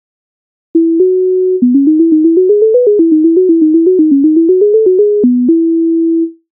MIDI файл завантажено в тональності E-dur